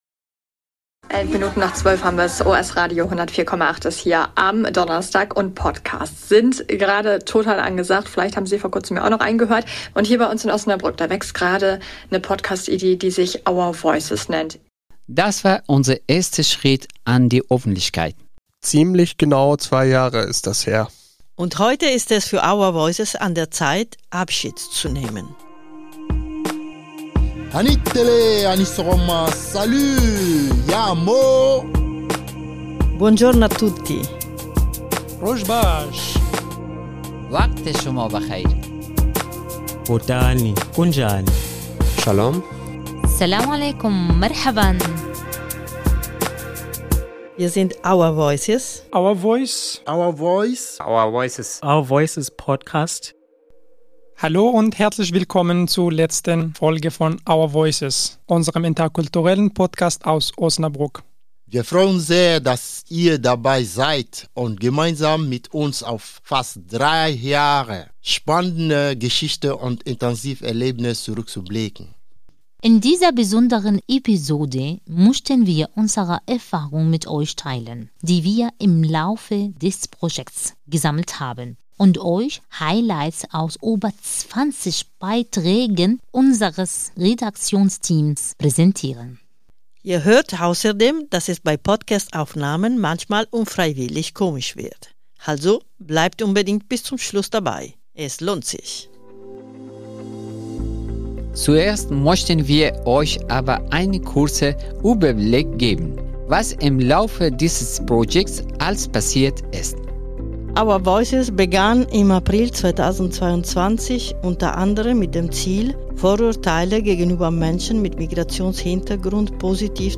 Unser ehrenamtliches Redaktionsteam blickt auf fast drei Jahre Projektlaufzeit zurück und teilt mit euch seine schönsten Erinnerungen, größten Herausforderungen und wichtigsten Erfahrungen. Es erwarten euch Highlights aus über 20 Beiträgen voller spannender Geschichten, bewegender Erlebnisse und unvergesslicher Momente – einige zum Lachen, andere zum Weinen. Außerdem erfahrt ihr, wie es sich anhört, wenn bei der Podcast-Produktion mal etwas schiefgeht ;) Natürlich nutzen wir auch die Gelegenheit, uns bei allen zu bedanken, die dieses Projekt möglich gemacht haben.